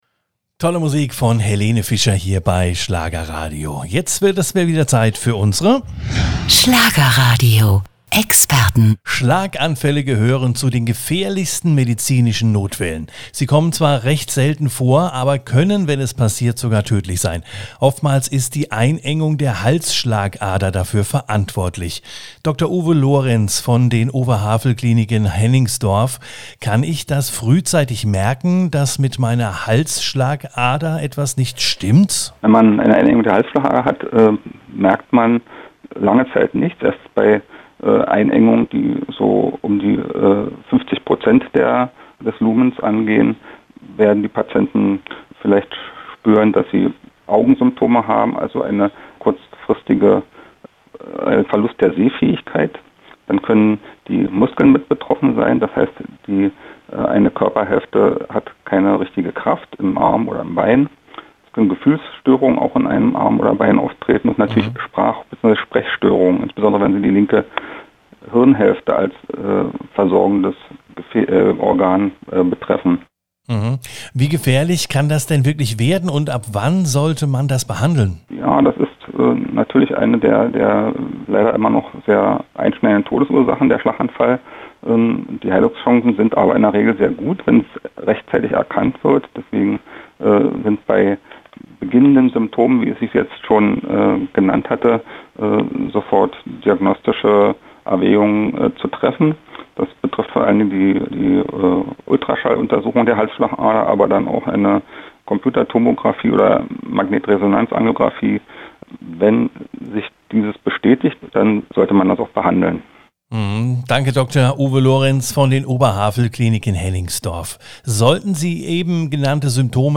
im Interview bei Schlagerradio.